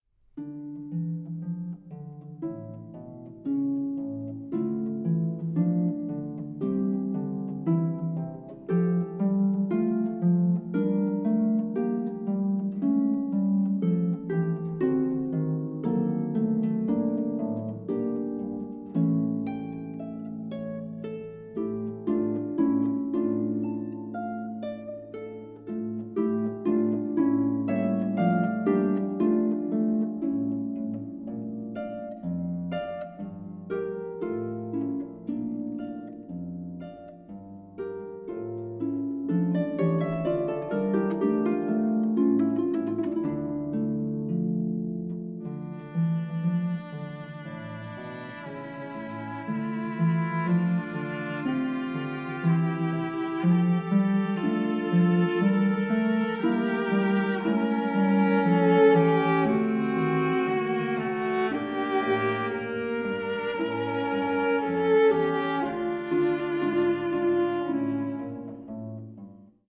CELLO MUSIC